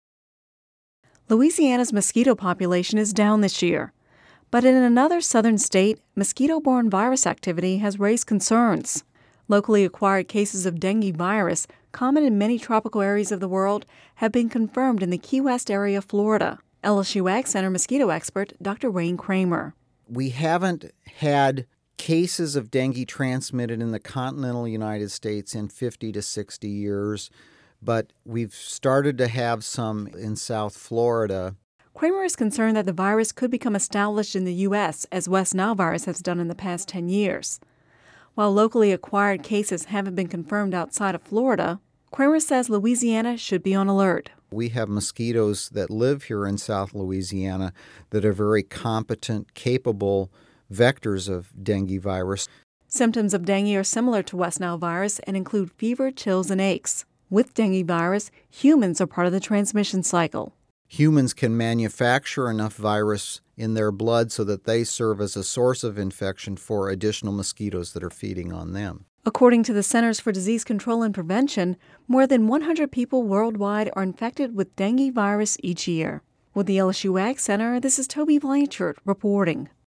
(Radio News 09/06/10) Louisiana’s mosquito population is down this year, but in another southern state, mosquito-borne virus activity has raised concerns.